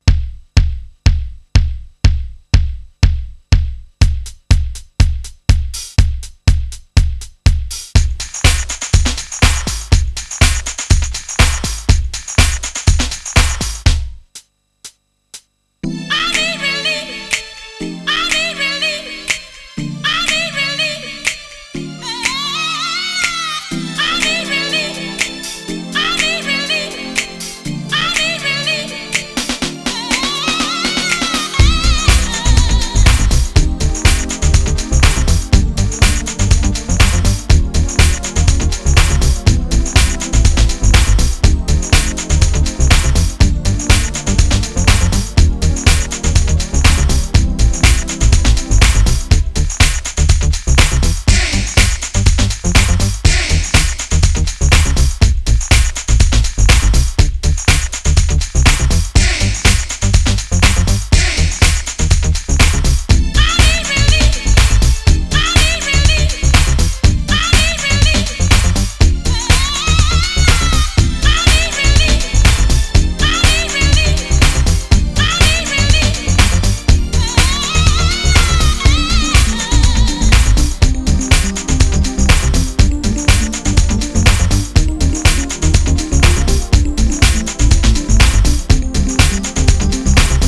ディープ・ハウスの真髄を突いている、といっても過言では無いでしょう。